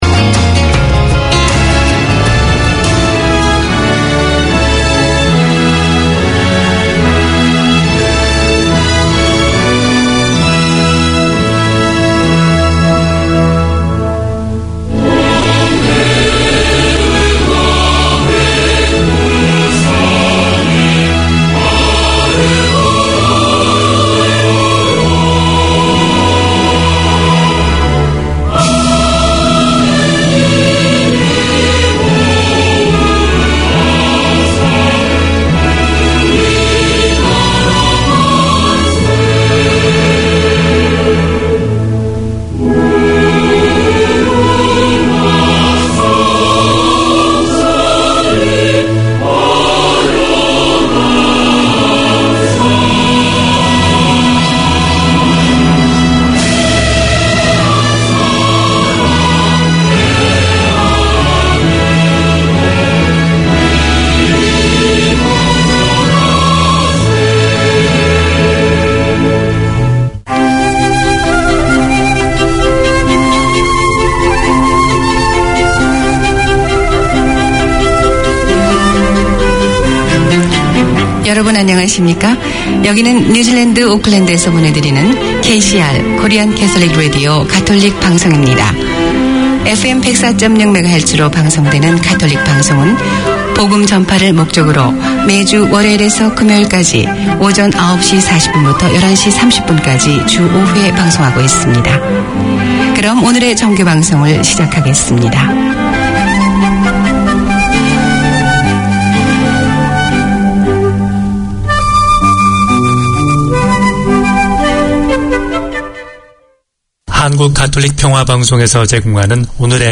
A magazine radio show for Koreans in Auckland. Daily news bulletins, music selections for all ages, devotional services and community information - sponsored by the Korean Catholic community.